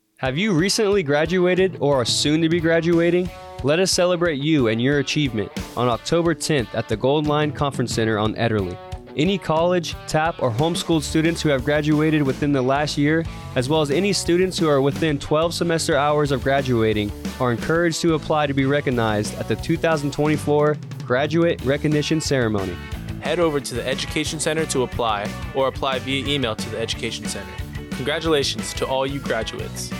AFN Radio Spot